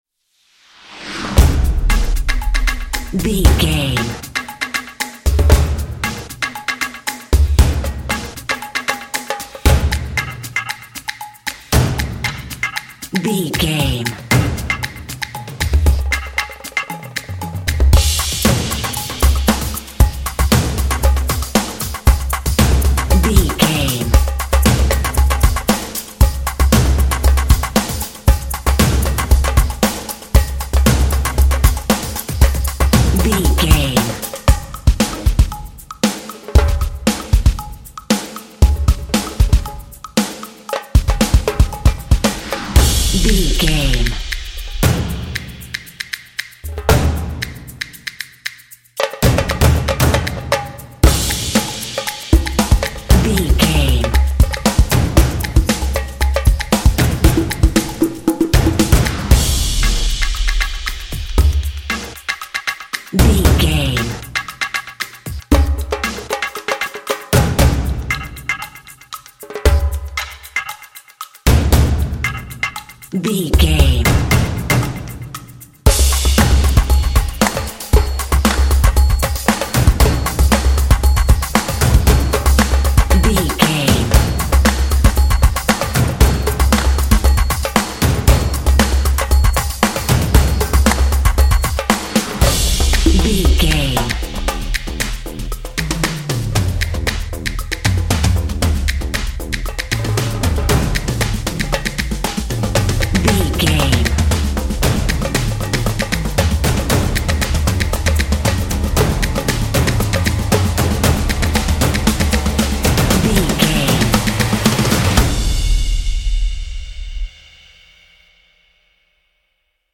Epic / Action
Atonal
groovy
intense
driving
energetic
drumline